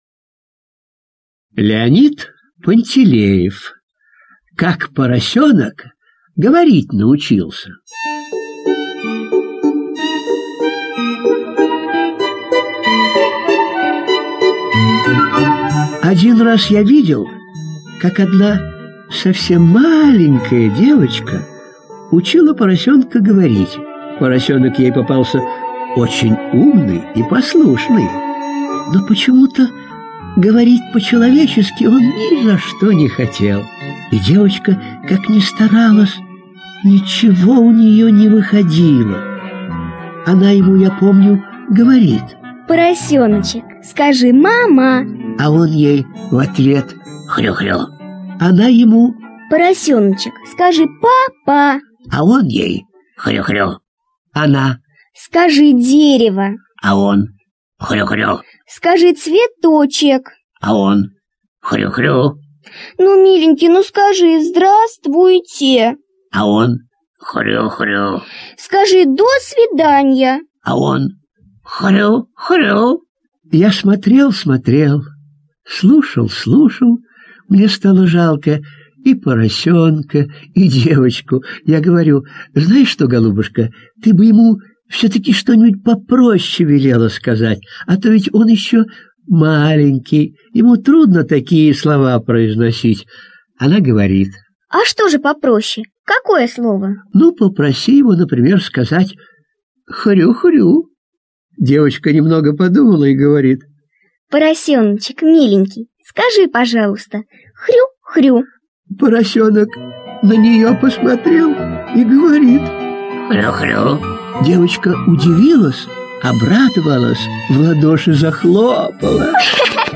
Слушайте Как поросенок разговаривать научился - аудио рассказ Пантелеева Л. Рассказ про маленькую девочку, учившую поросенка разговаривать.